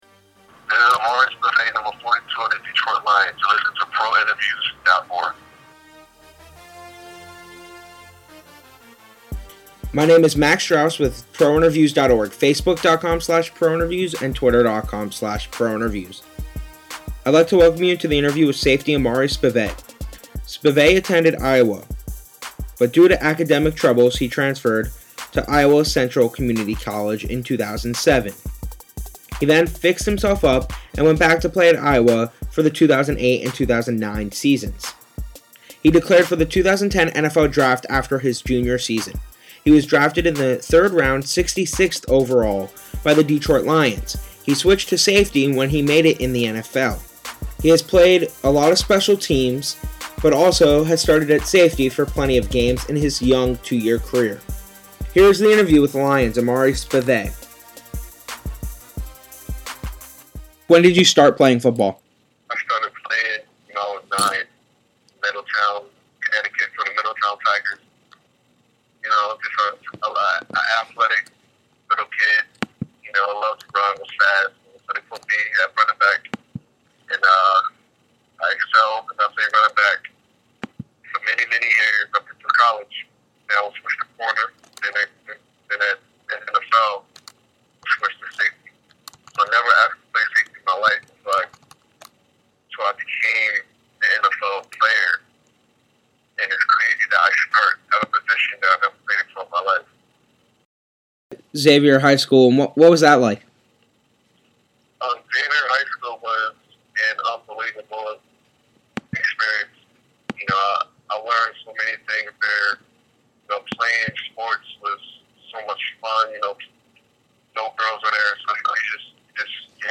Lions FS, Amari Spievey Interview
interview-with-amari-spievey.mp3